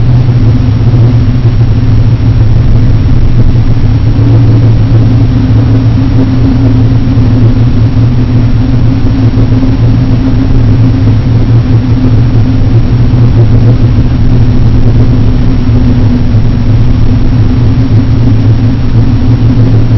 ambience
thrum.wav